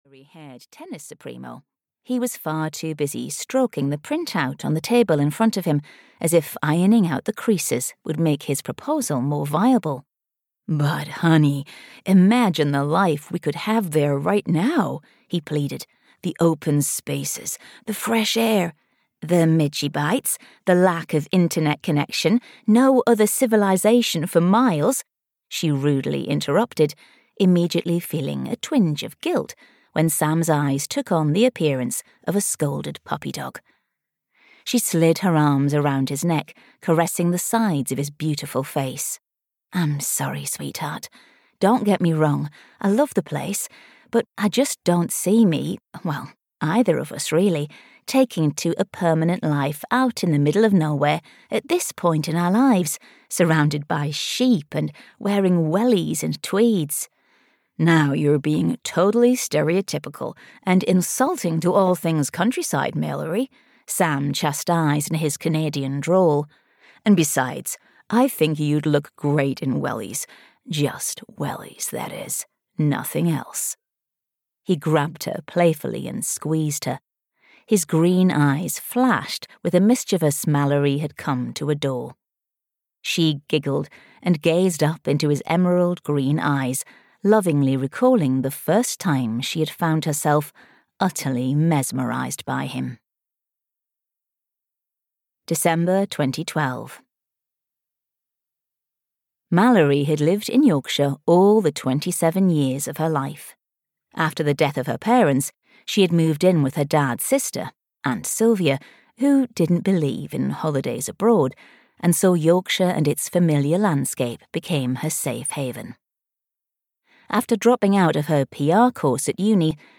A Seaside Escape (EN) audiokniha
Ukázka z knihy